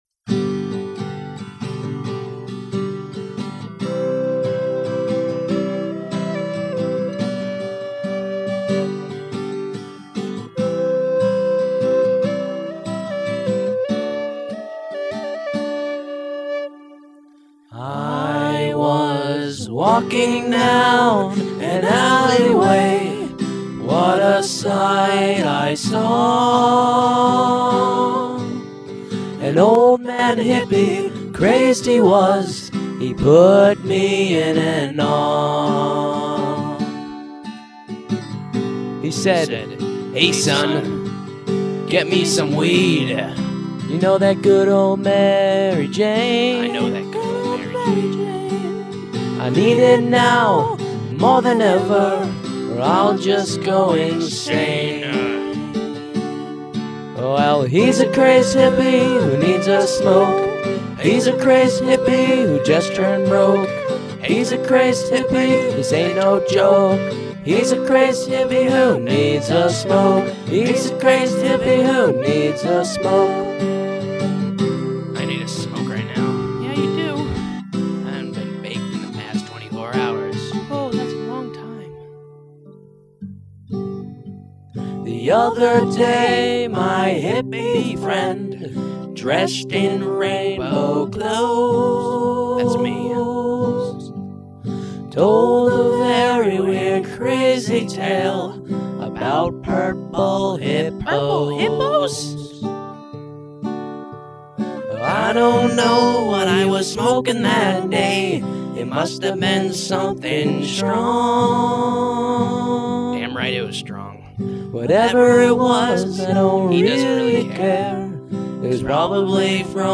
I need a smoke! lol Funny song, very silly ;)
the hippie sounds like Beavis!